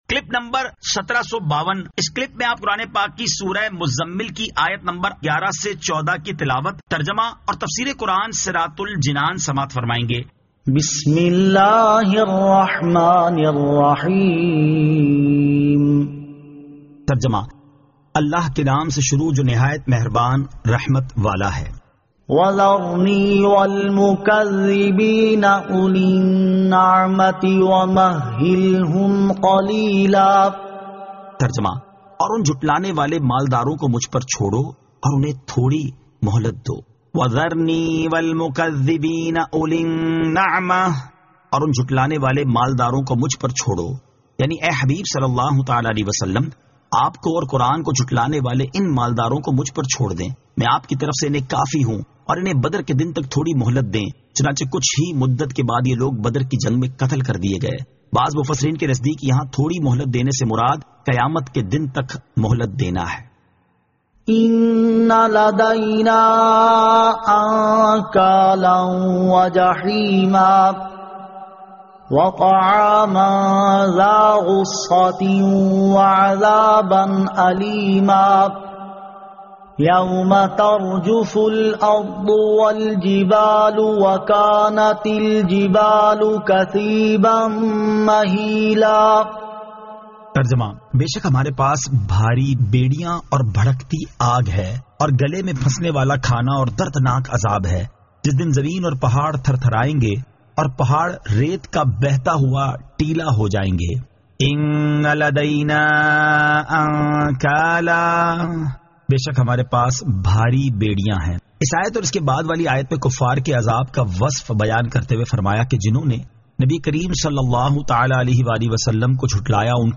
Surah Al-Muzzammil 11 To 14 Tilawat , Tarjama , Tafseer